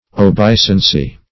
Obeisancy \O*bei"san*cy\, n.
obeisancy.mp3